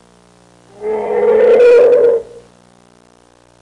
Elephant Sound Effect
Download a high-quality elephant sound effect.
elephant.mp3